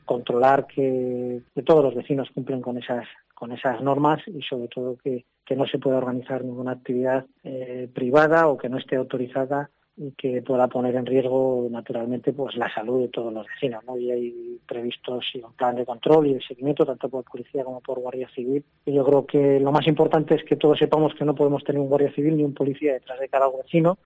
Roberto Díez, alcalde de Peñafiel, sobre los controles de este fin de semana